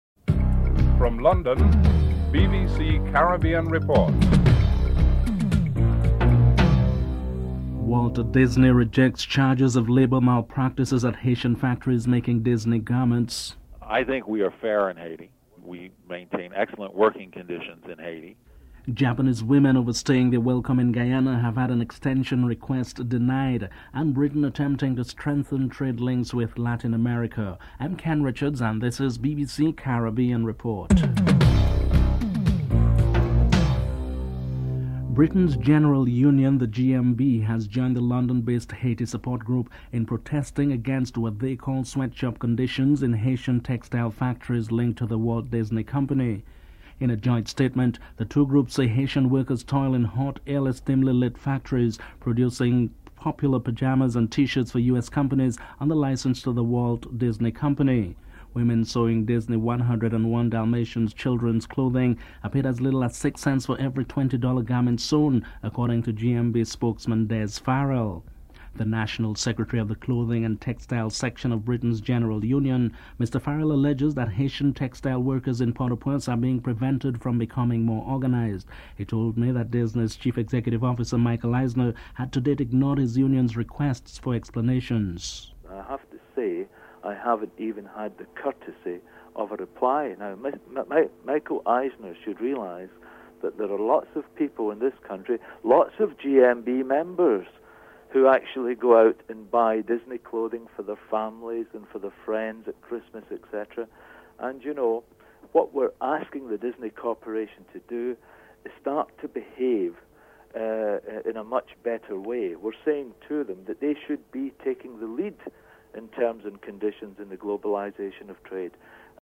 The British Broadcasting Corporation
1. Headlines (00:00-00:33)